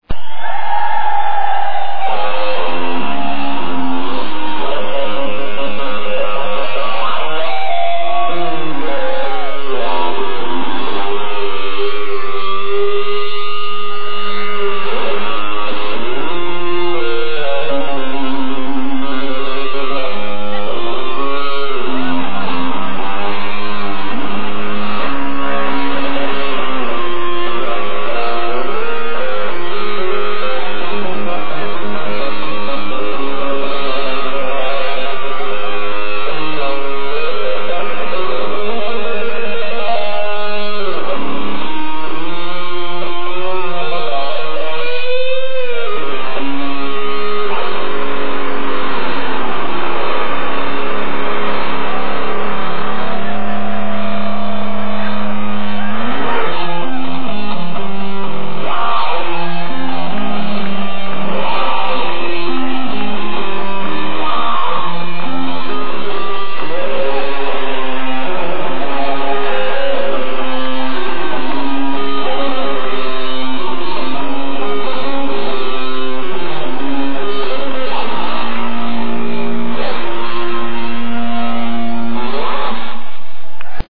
Stockholm, Sweden 26 November 1986
bass solo